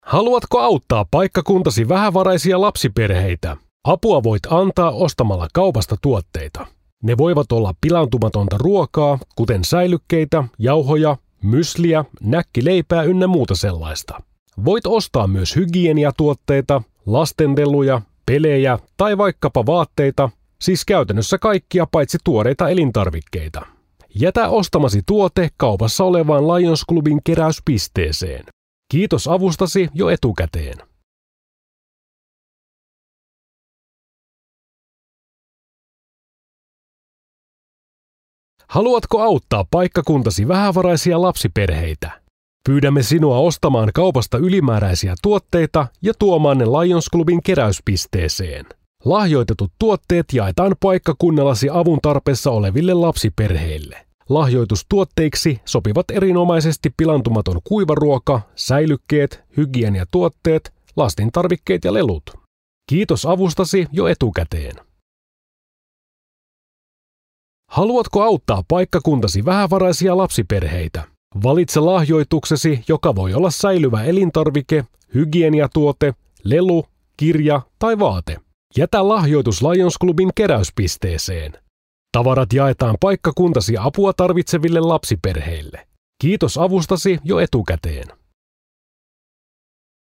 • UUTTA! Myymäläkuulutuksia (mp3) (
lionsclub_myymala_spiikit.mp3